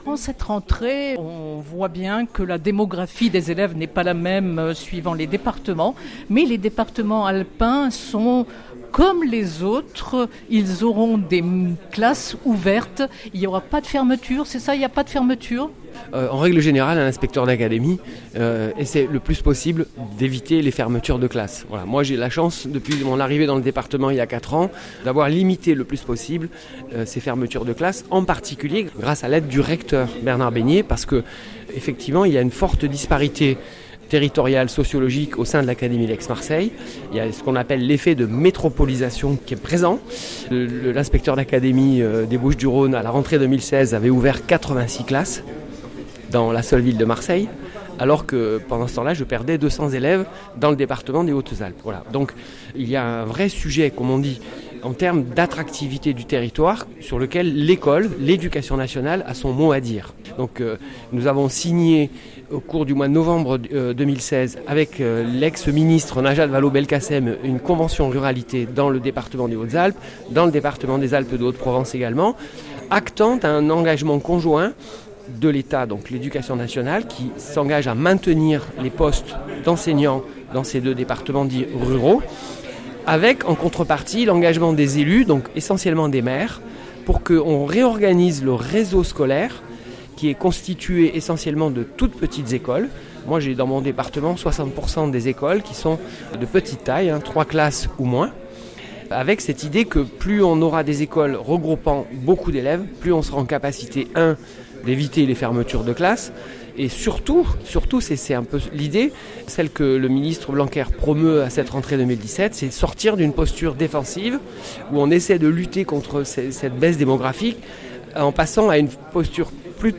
Philippe Maheu, Inspecteur d’académie – Directeur académique des services de l’Éducation nationale (IA-DASEN) des Hautes Alpes apporte quelque éclairage, évoquant notamment “la convention ruralité ”